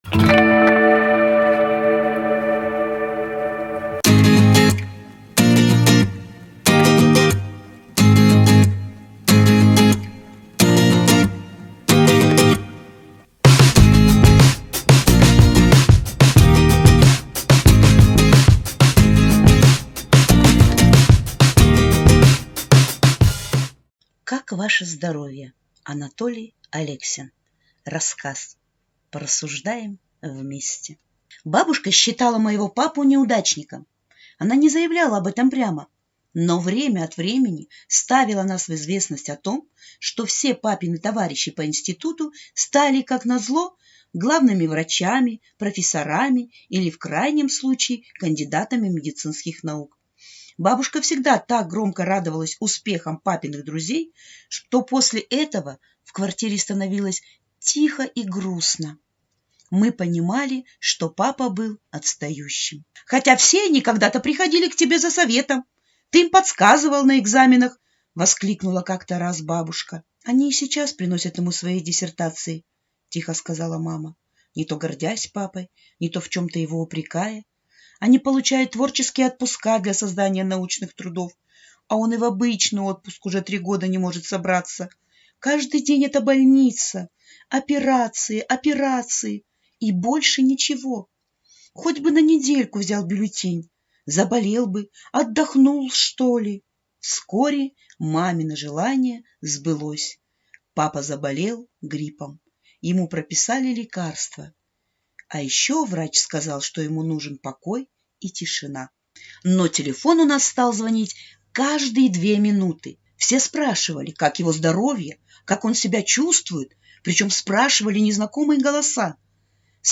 Аудиорассказ «Как ваше здоровье?»